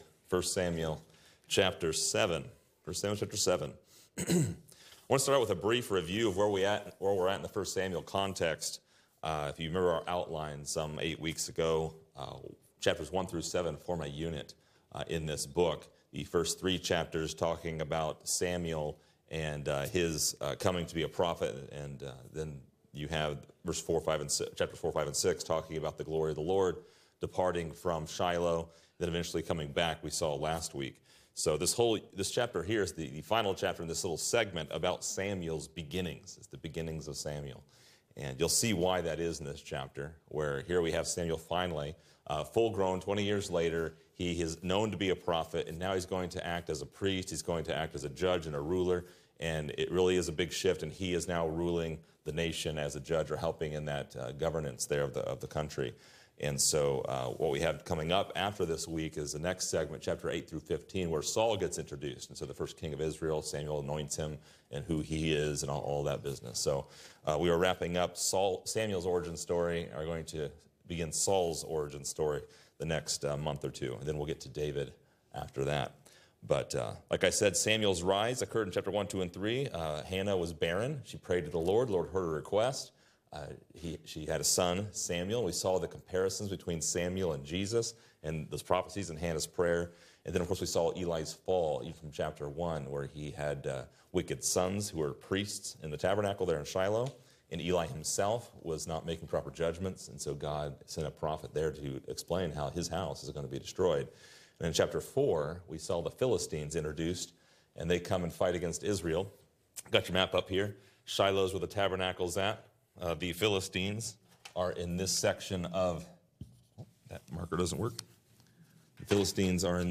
Description: This lesson is part 8 in a verse by verse study through 1 Samuel titled: Ebenezer: Stone of Help